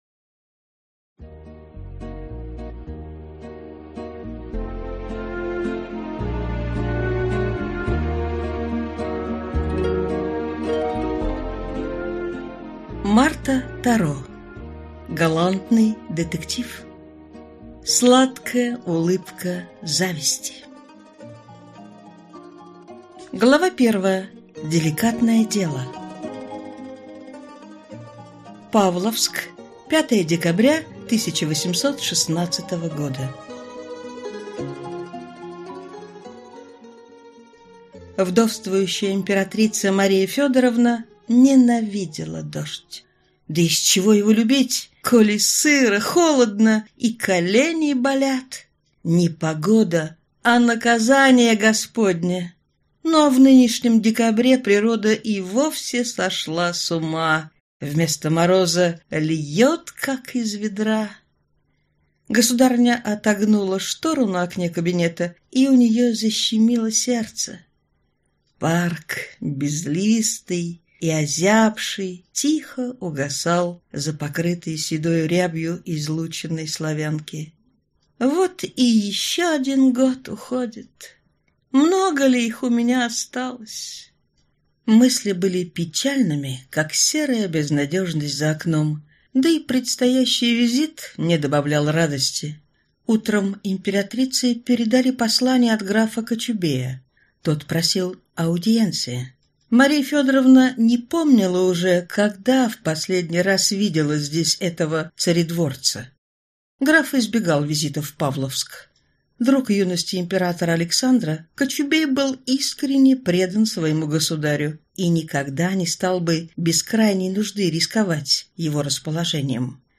Аудиокнига Сладкая улыбка зависти | Библиотека аудиокниг